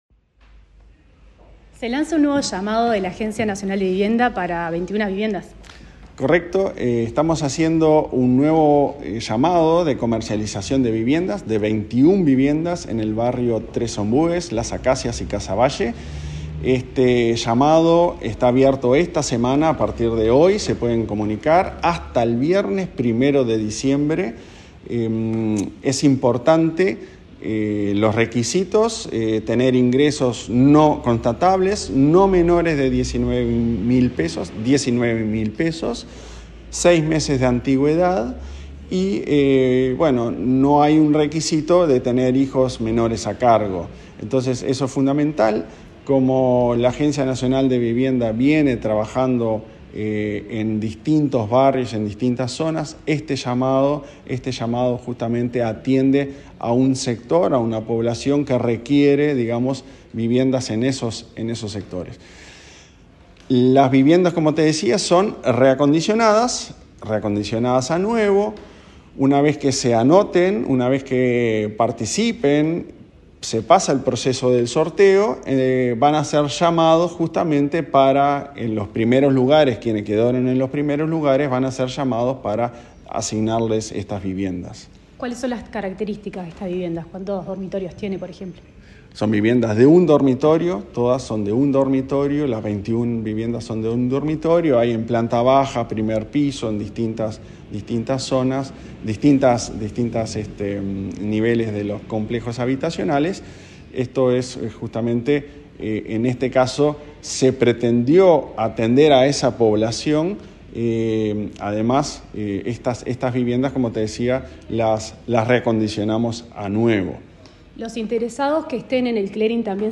Entrevista al presidente de la ANV, Klaus Mill von Metzen